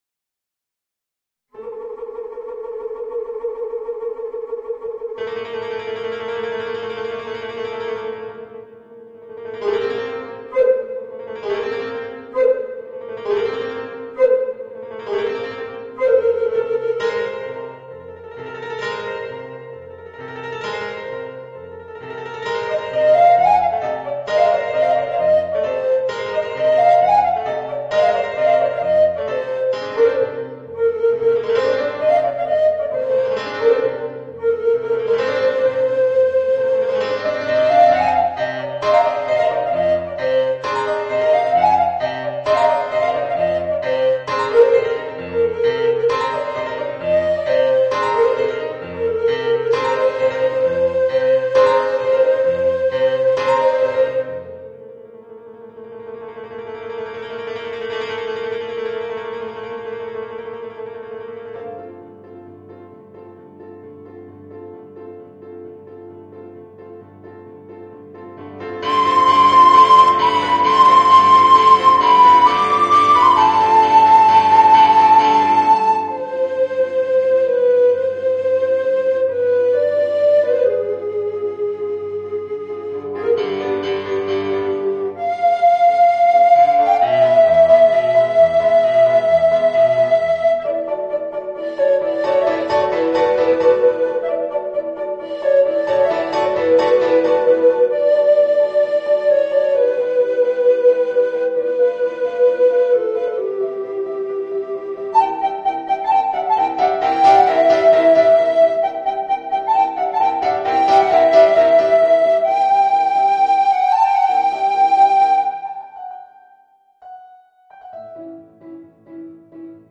Voicing: Alto Recorder and Piano